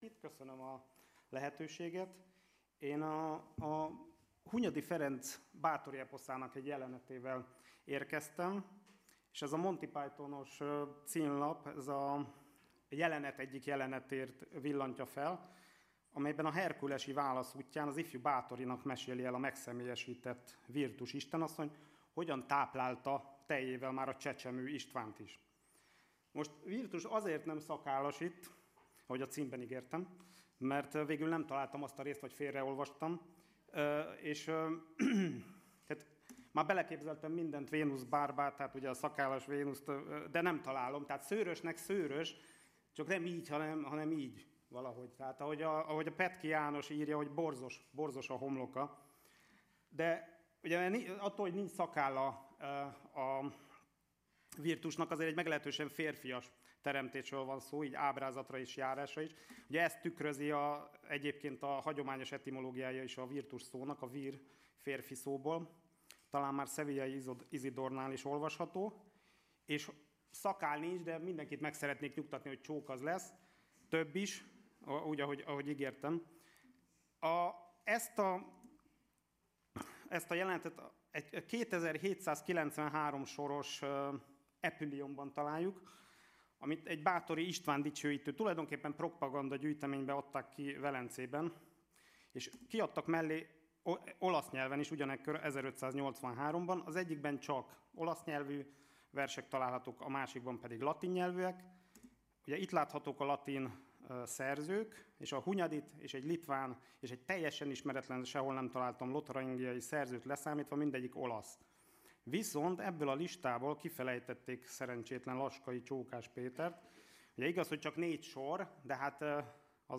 (lecturer)